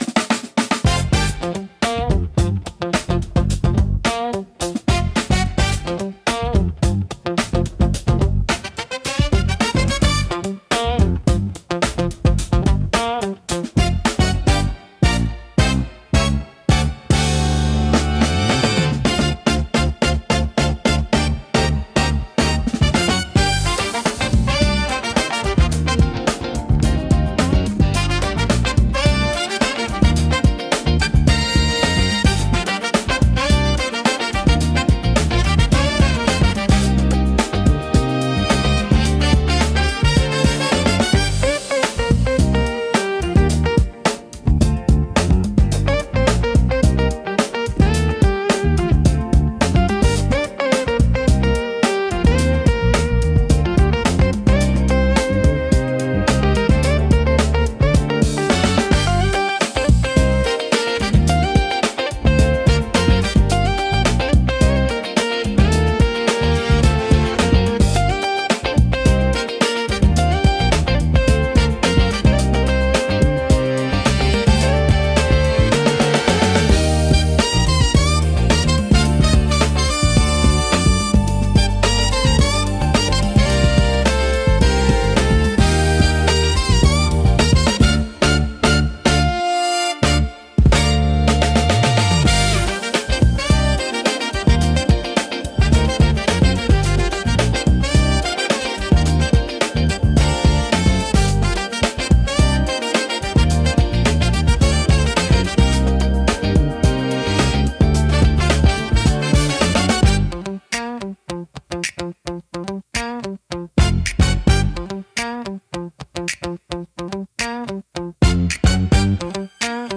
心地よいリズムが聴く人を踊らせ、楽しい雰囲気を盛り上げる効果があります。躍動感と活気に満ちたジャンルです。